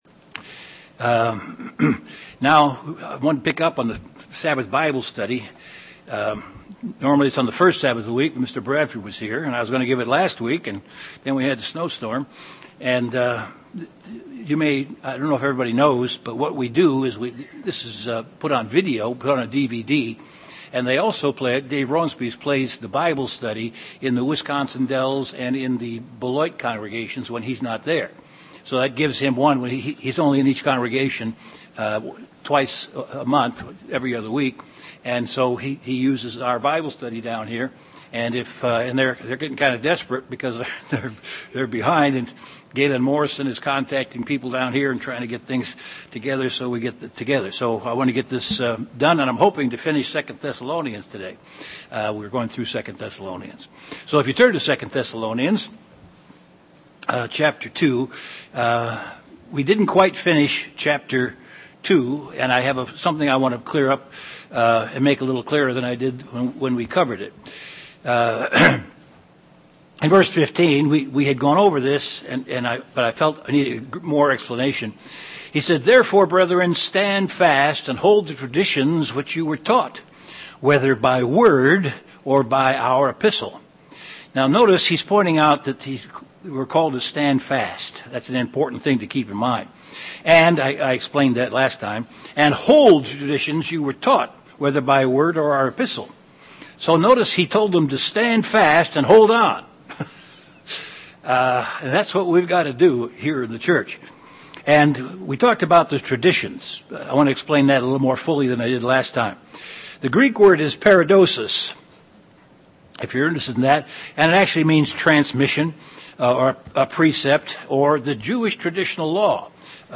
Given in Chicago, IL Beloit, WI
UCG Sermon Studying the bible?